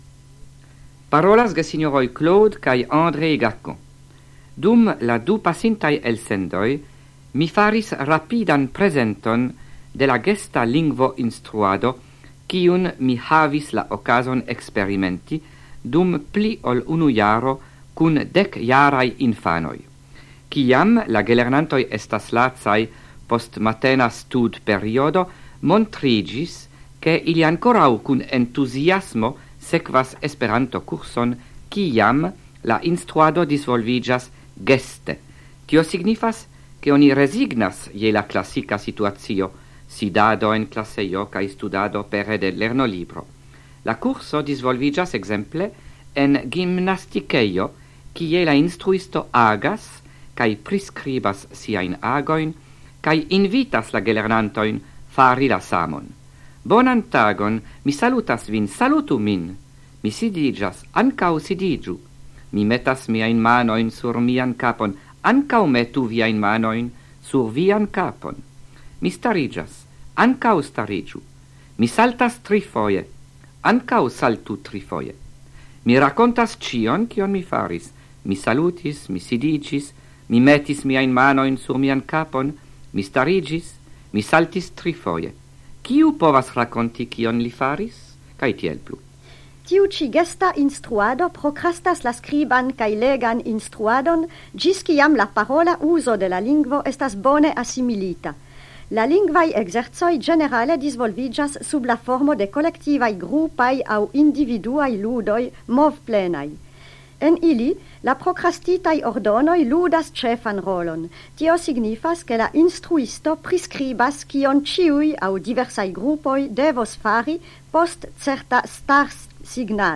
Radioprelegoj en la jaro 1979